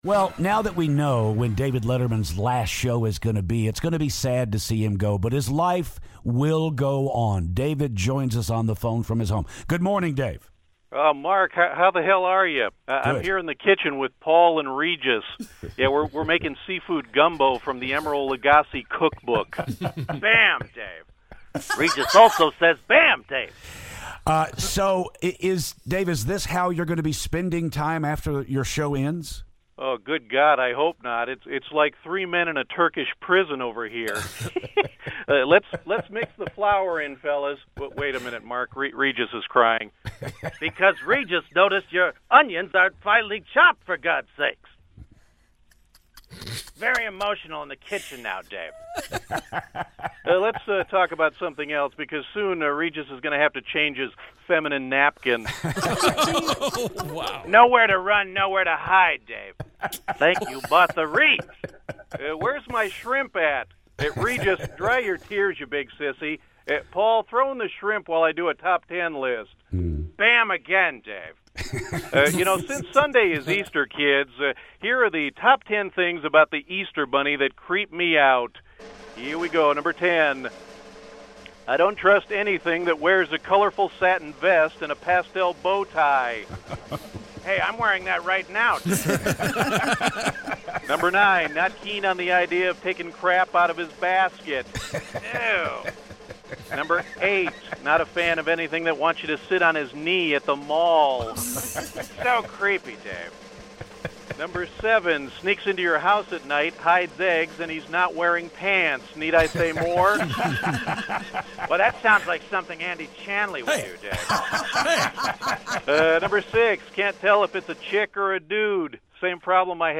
David Letterman Phoner